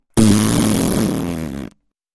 Звуки высовывания языка
Звук выдувания воздуха с высунутым языком